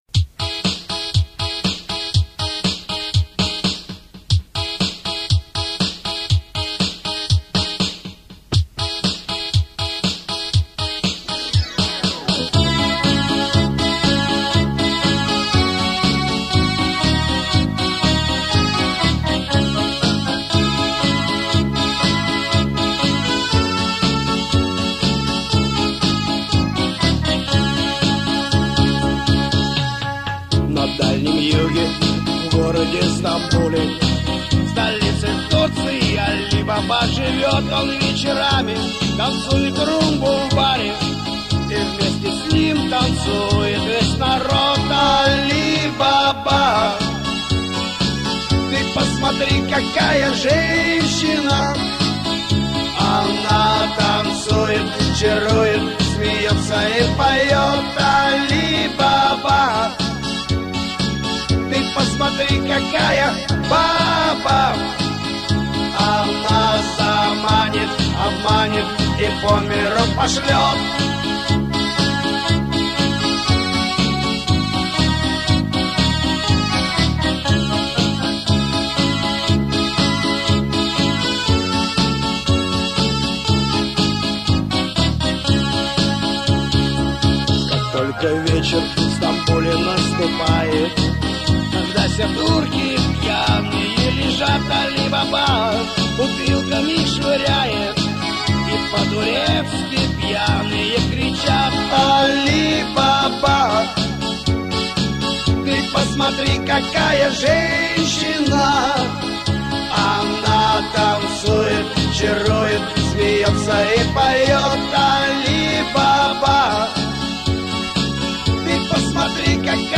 Дворовые_и_лагерные_песни_Классика_жанра
Dvorovye_i_lagernye_pesni_Klassika_zhanra.mp3